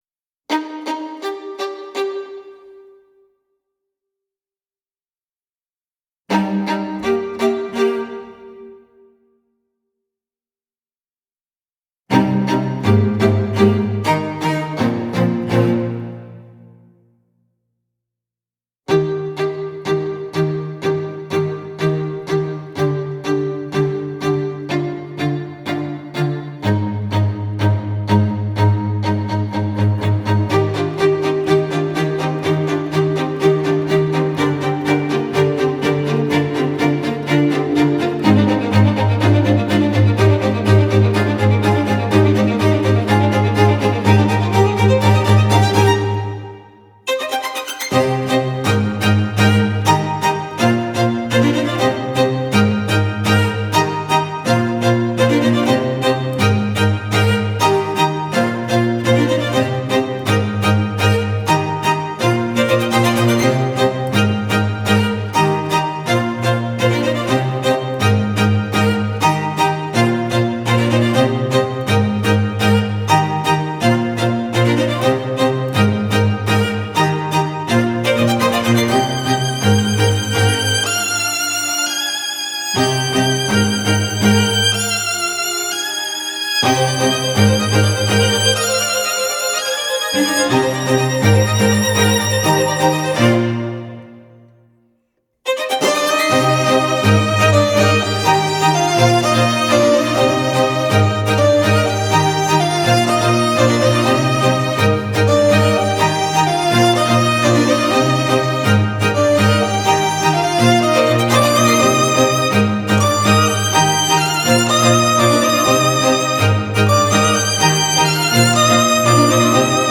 • Жанр: Instrumental